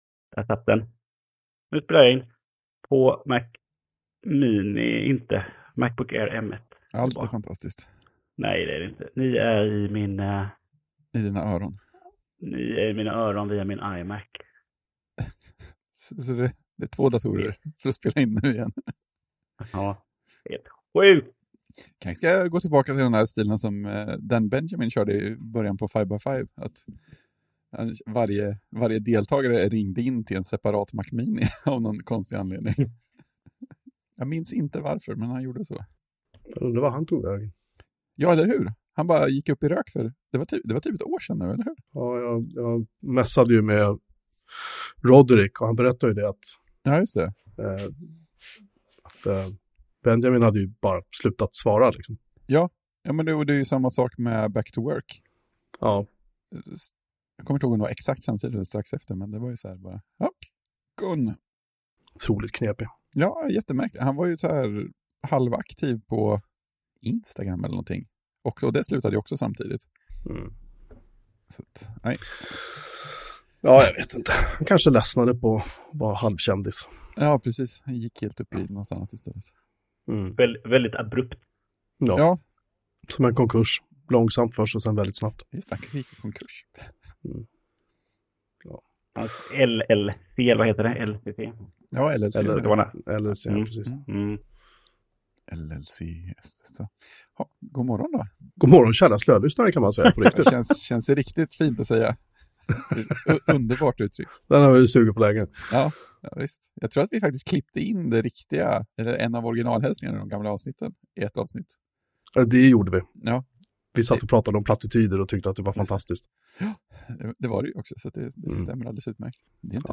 ljud är av oklara anledningar frasigare än vanligt